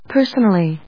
音節per・son・al・ly 発音記号・読み方
/pˈɚːs(ə)nəli(米国英語), pˈəːs(ə)nəli(英国英語)/